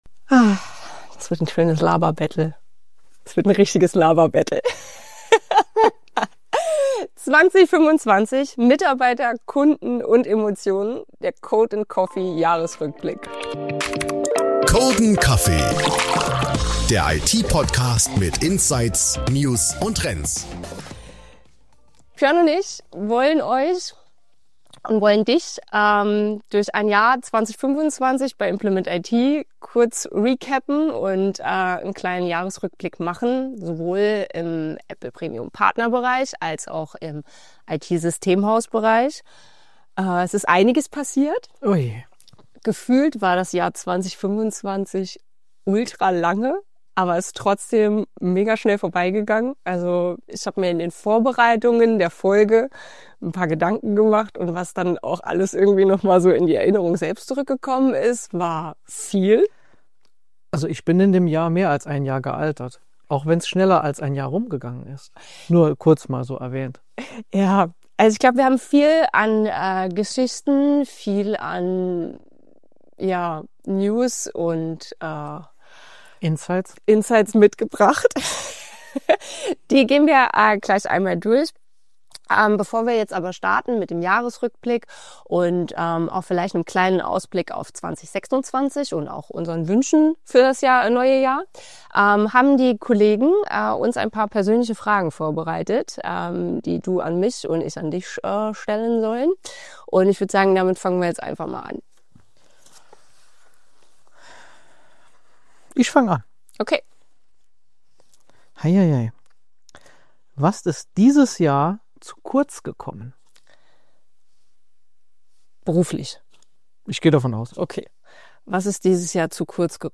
Gemütlich aus der Couchecke, mit weihnachtlicher Stimmung und einem Dank an alle, die 2025 zu dem gemacht haben, was es war.